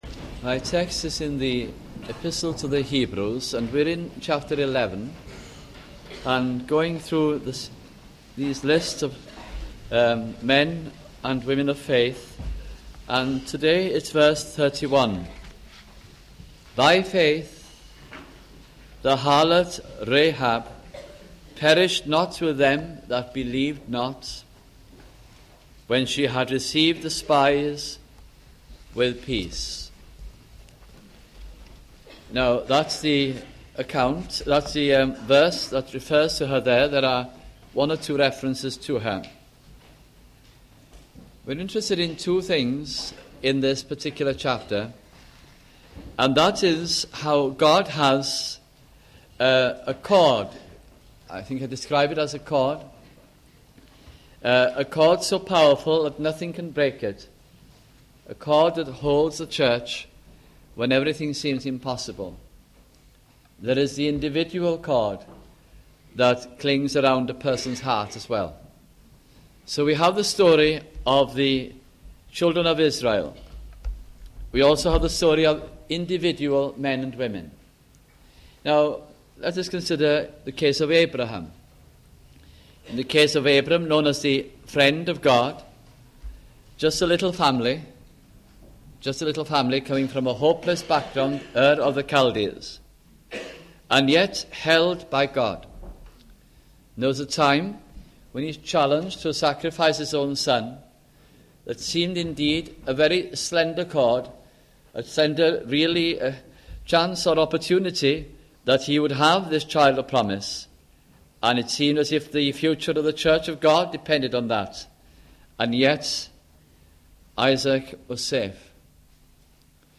» The Epistle to the Hebrews 1984 - 1986 » sunday morning messages